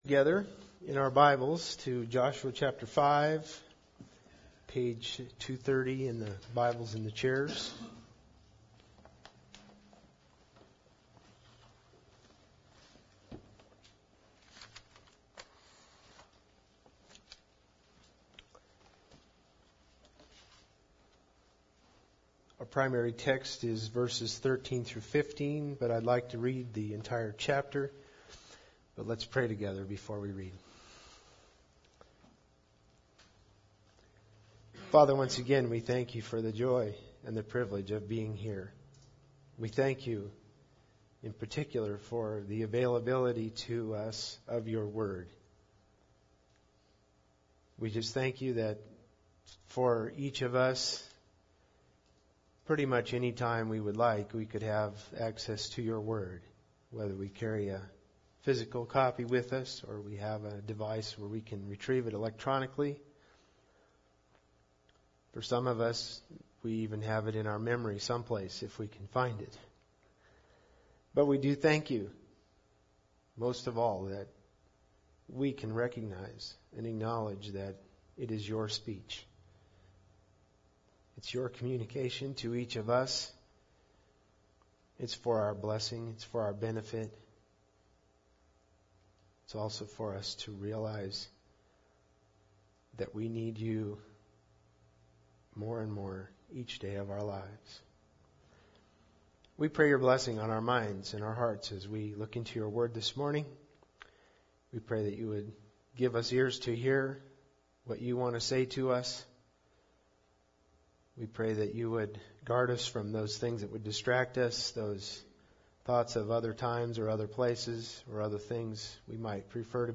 Joshua 5:13-15 Service Type: Sunday Service Bible Text